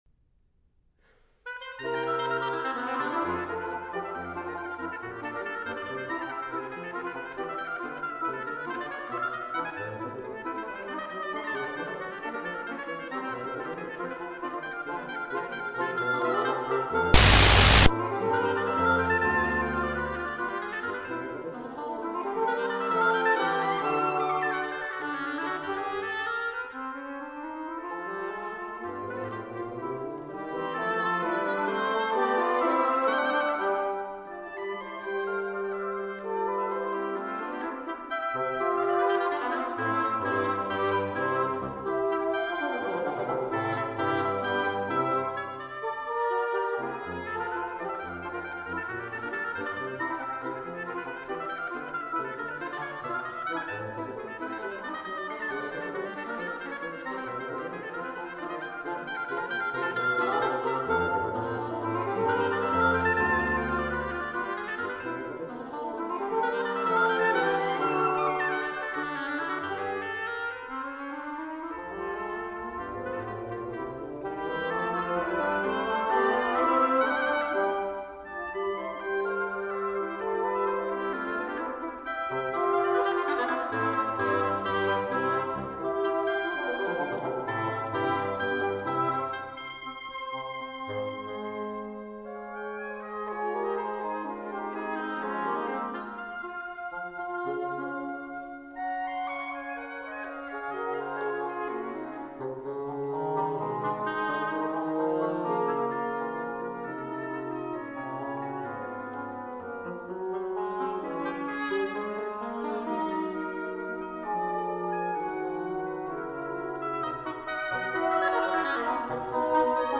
Allegro molto vivace